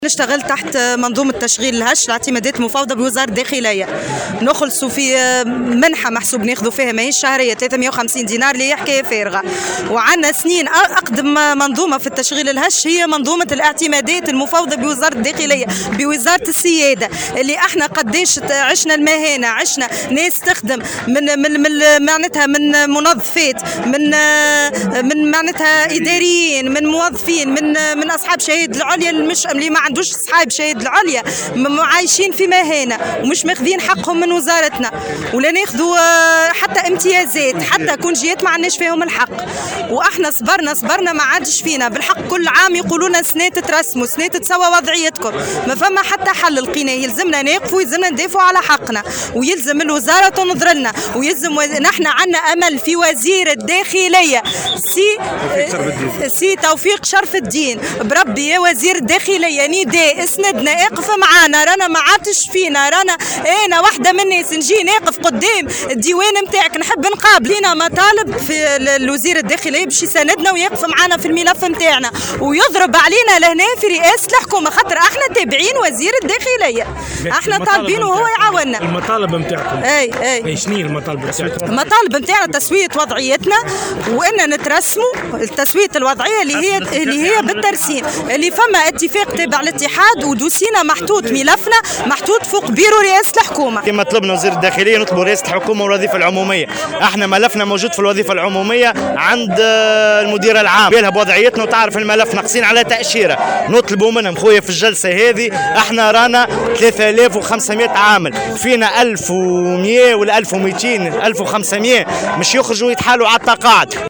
نفذ صباح اليوم الاثنين، أعوان الاعتمادات المفوضة بوزارة الداخلية وقفة احتجاجية بساحة الحكومة بالقصبة.